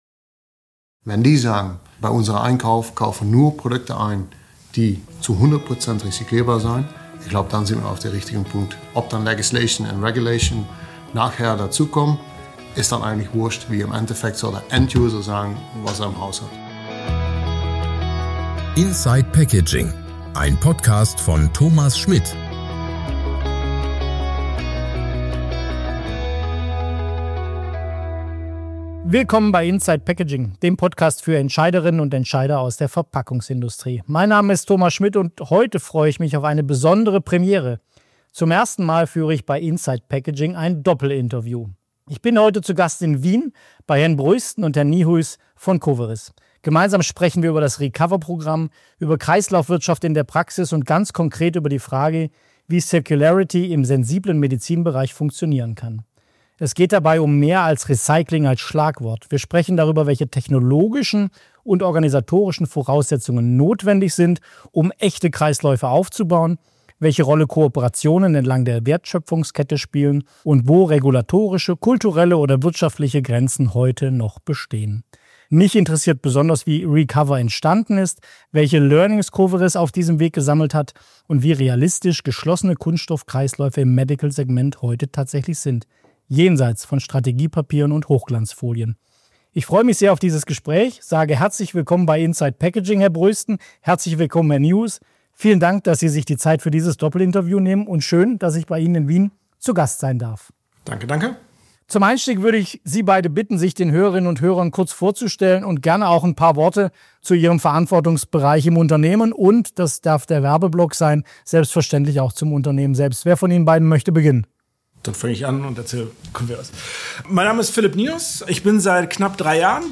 Beschreibung vor 6 Tagen In dieser Folge spreche ich mit zwei Experten von Coveris über die Herausforderungen und Chancen echter Kreislaufwirtschaft im Medizinbereich. Wir tauchen tief ein in das Recover-Programm, diskutieren, wie technologische, regulatorische und kulturelle Hürden überwunden werden können, und zeigen, warum Kooperationen entlang der Wertschöpfungskette entscheidend sind.